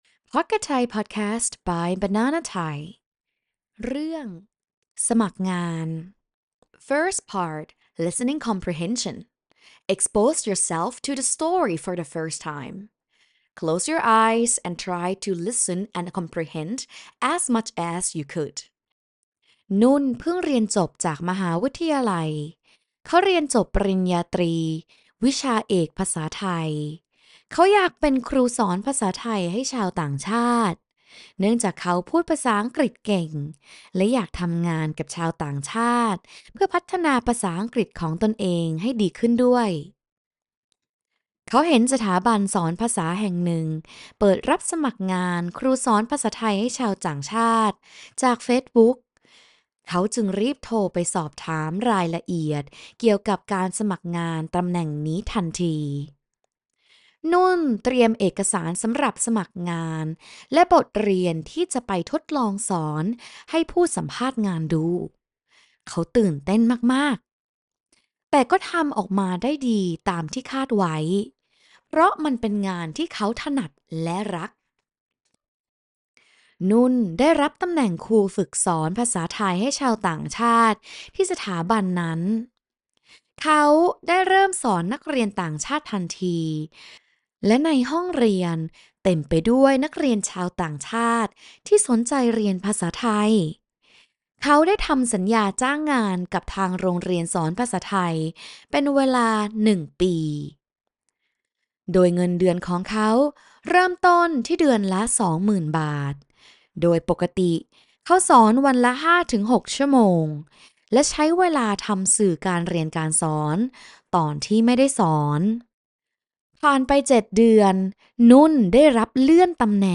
Part 2: Learn with Video Lesson